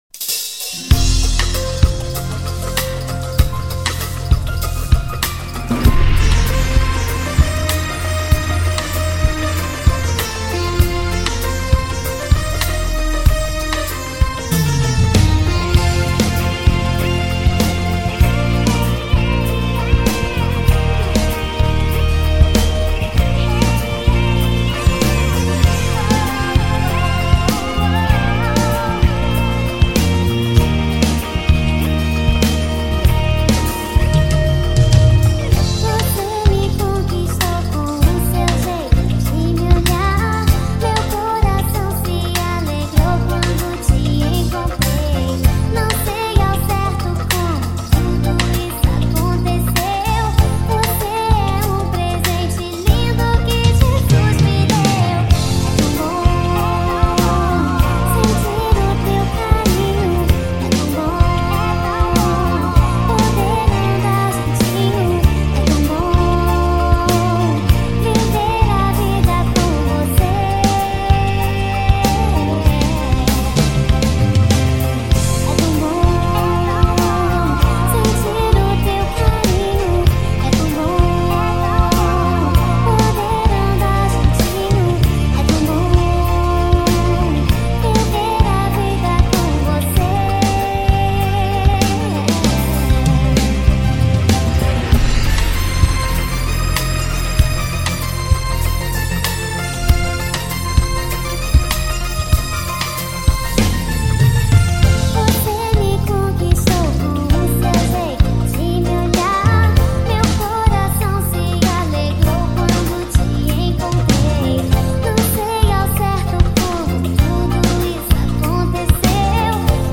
EstiloPop